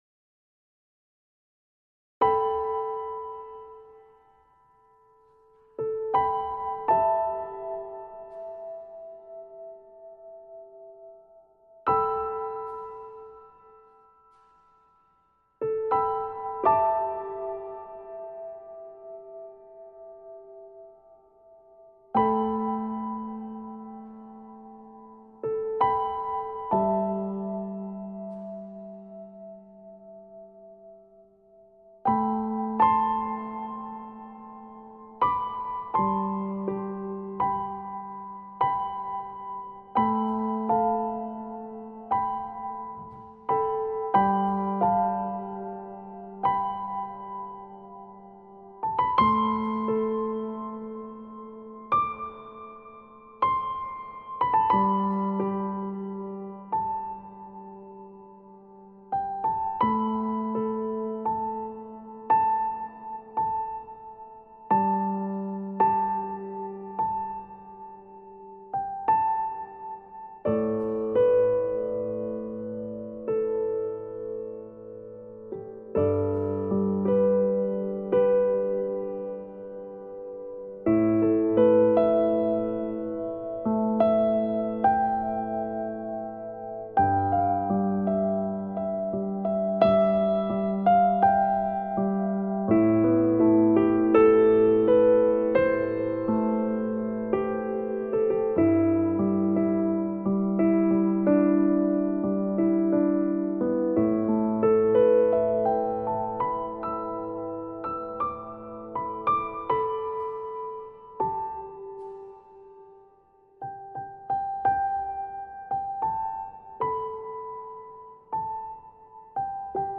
это мелодичная композиция в жанре инди-поп